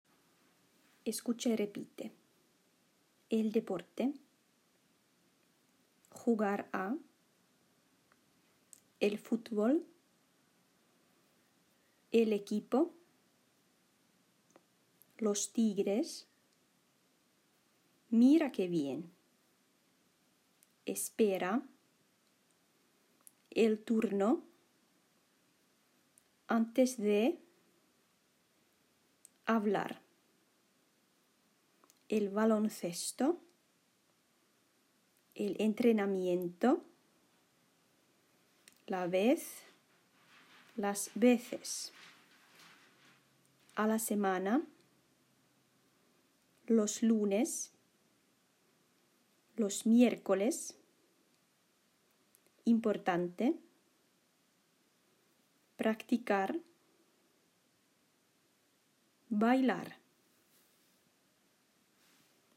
Lue ensin ääneen keltaisen laatikon sanasto open perässä eli paina alta play, katso omaa tekstikirjaa ja toista järjestyksessä sanat open perässä.
Kuuntele ja toista sanat s. 27 open perässä tästä: